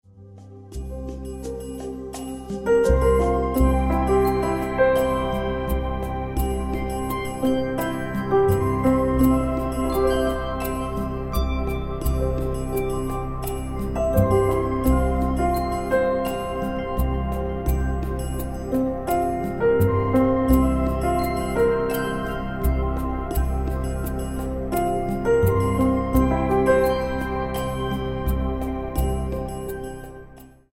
84 BPM
New age piano punctuates a pad of flutes and wispy textures.